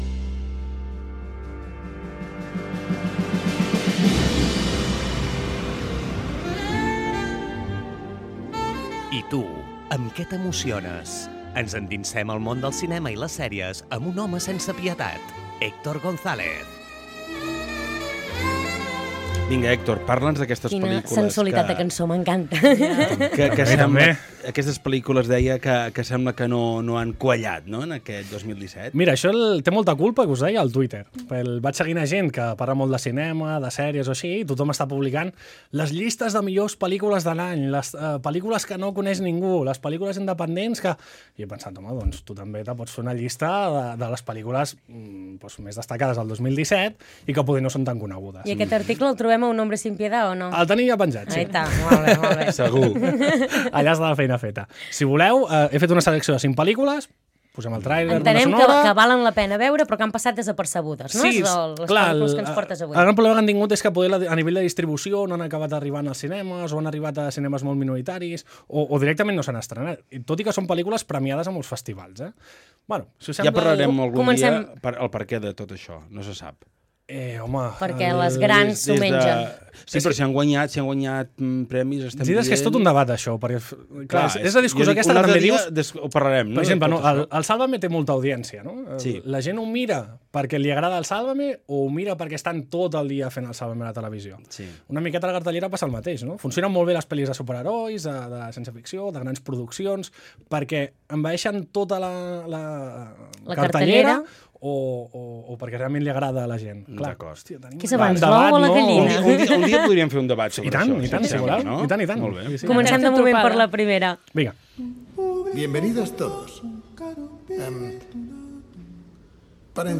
Quinta colaboración con el programa “La Tropa” de la emisora de radio “Fem Girona”.